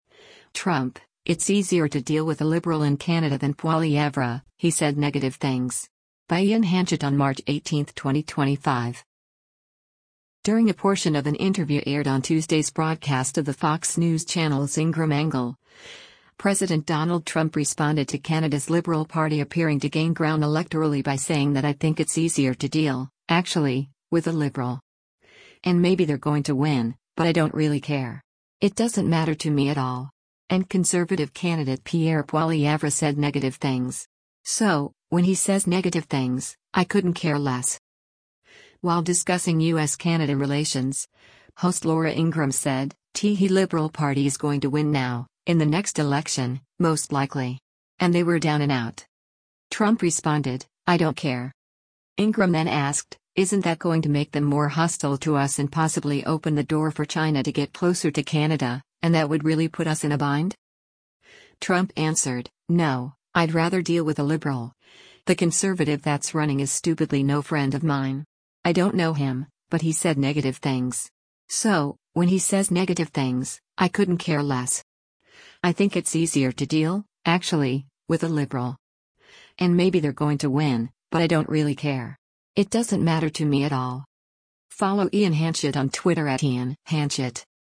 During a portion of an interview aired on Tuesday’s broadcast of the Fox News Channel’s “Ingraham Angle,” President Donald Trump responded to Canada’s Liberal Party appearing to gain ground electorally by saying that “I think it’s easier to deal, actually, with a Liberal. And maybe they’re going to win, but I don’t really care. It doesn’t matter to me at all.” And Conservative candidate Pierre Poilievre “said negative things. So, when he says negative things, I couldn’t care less.”
While discussing U.S.-Canada relations, host Laura Ingraham said, “[T]he Liberal Party is going to win now, in the next election, most likely. And they were down and out.”